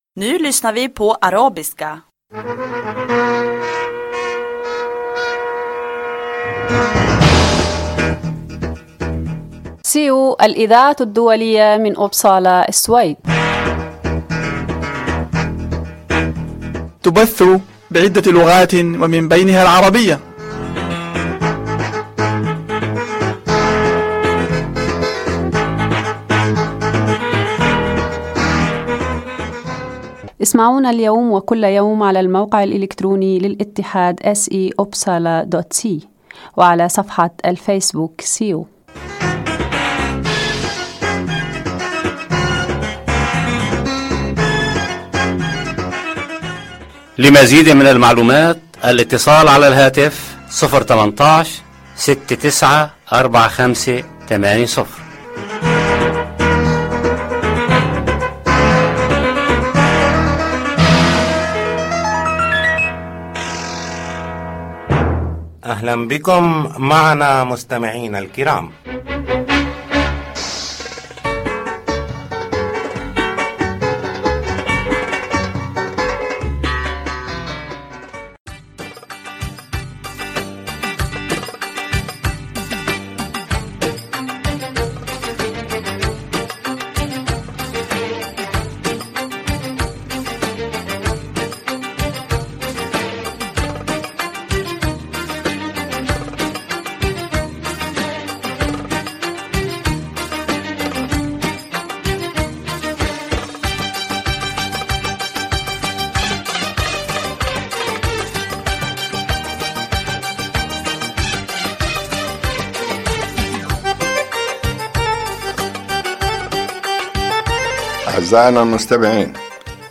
يتضمن البرنامج أخبار من مدينة إبسالا و السويد تهم المهاجرين و برامج ترفيهية و مفيدة أخرى. برنامج هذا الأسبوع يتضمن أخبار الاتحاد السيو، من أخبارنا المحلية و مقتطفات من الصحف العربية و أخبار متنوعة ومقابلات مع ضيوف السيو ومن الشعر و الموسيقى .